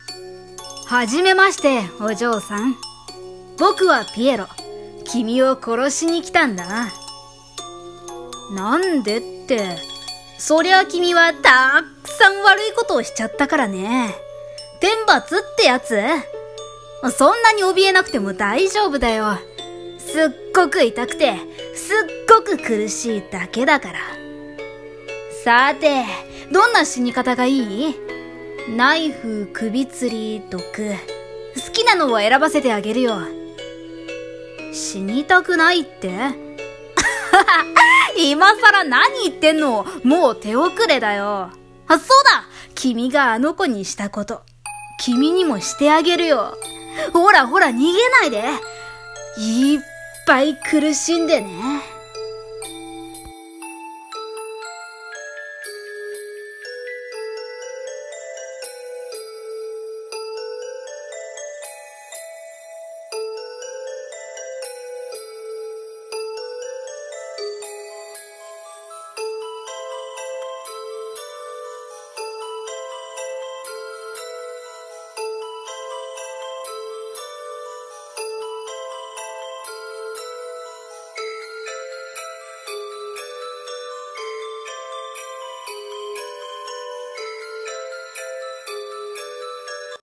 【声劇】道化師